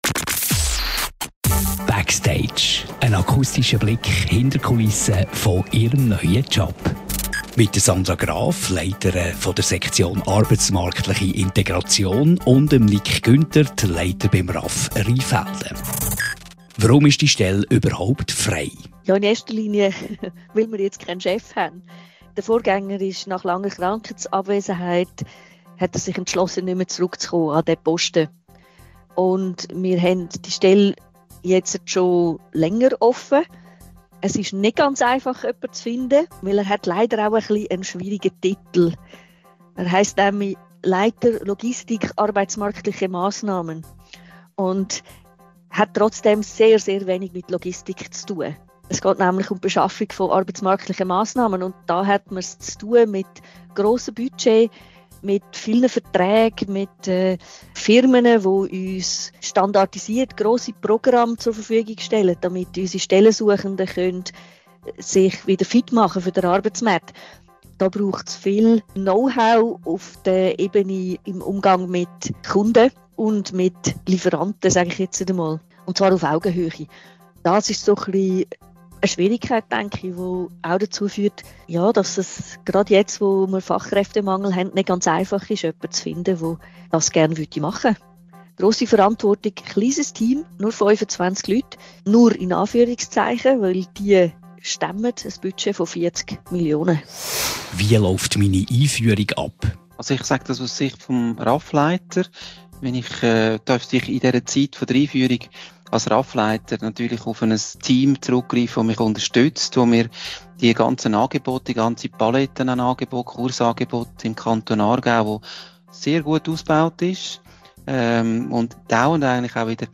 Zusätzlich zur Stellenanzeige beantworten Vorgesetzte, Personalverantwortliche oder Arbeitskolleginnen und Arbeitskollegen vom Kanton Aargau fünf Fragen zum Job und dem Drumherum.